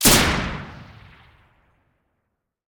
generalgun.ogg